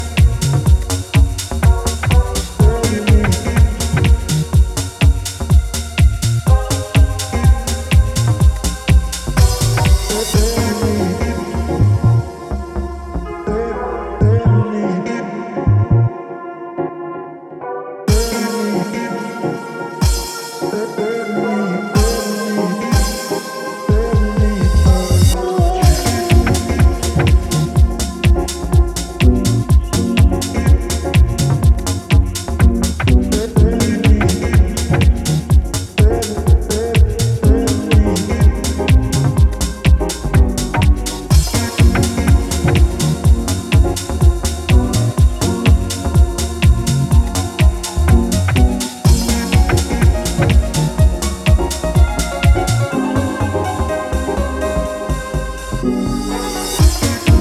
ここでは、ジャジーで豊潤なメロディーが情感を駆り立てる、ウォームでグルーヴィーなディープ・ハウス群を展開。